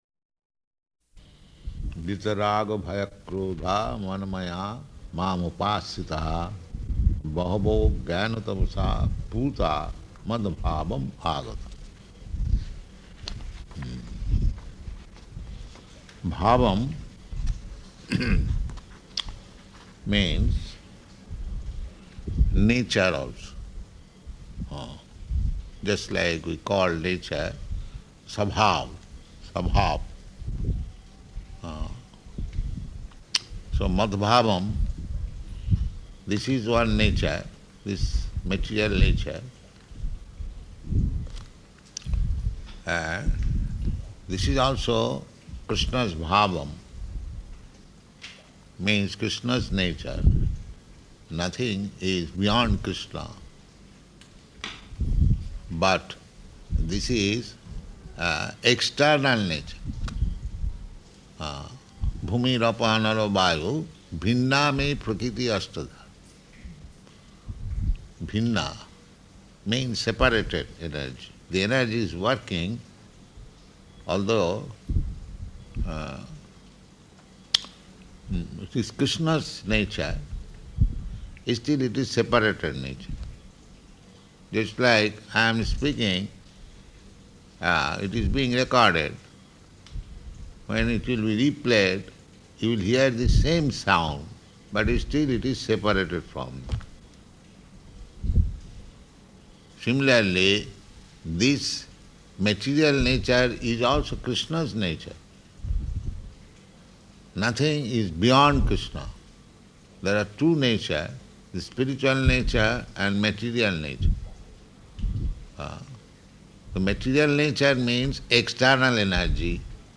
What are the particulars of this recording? Location: Vṛndāvana